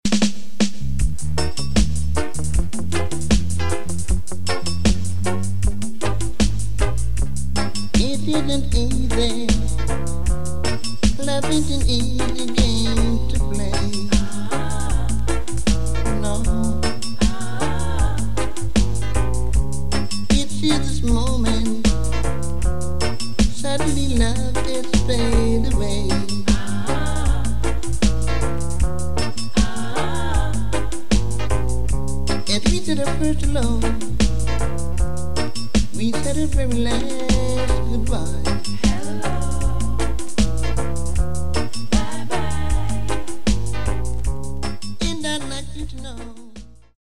Genere: Reggae, Dancehall, Lovers Rock